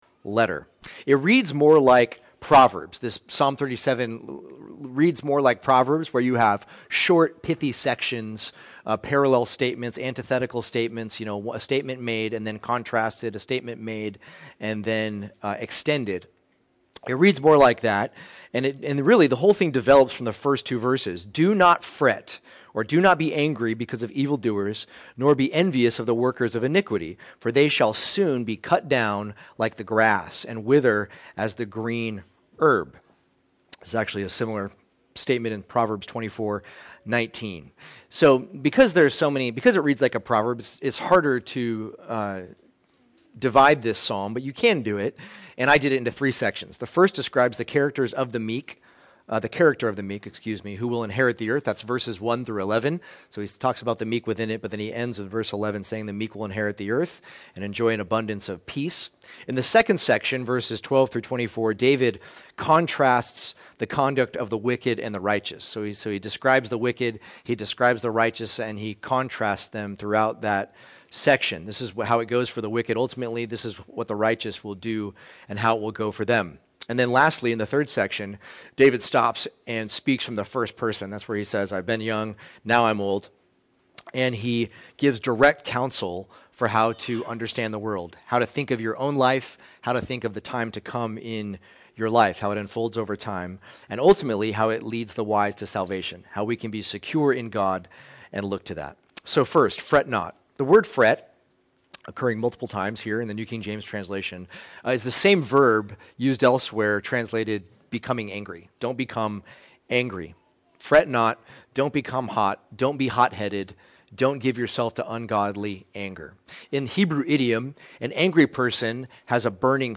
The Inheritance of the Meek | Emmanuel Church